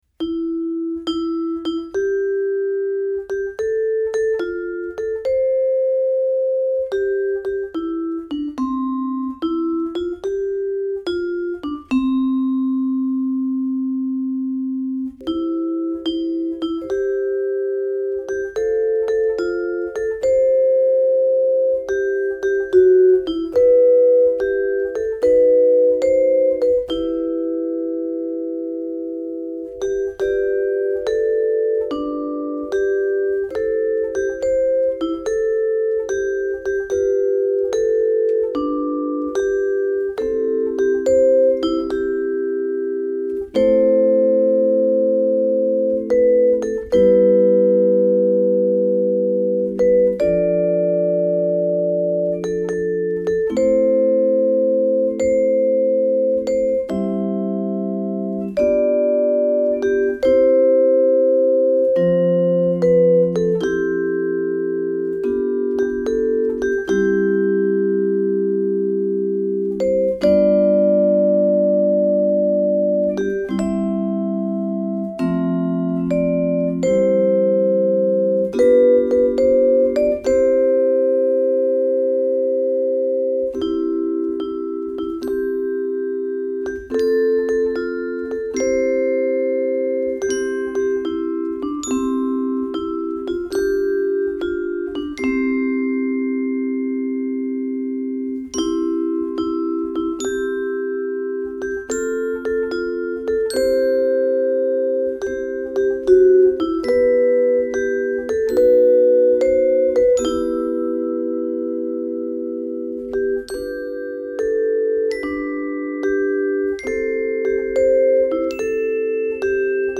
Christmas Songs on Percussion